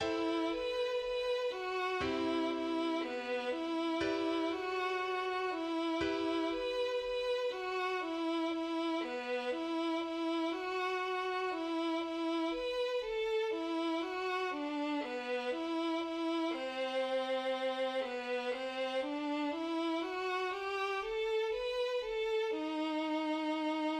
midiInstrument = "violin"
\time 3/4 \tempo "Presto" 4 = 120 \key mi \minor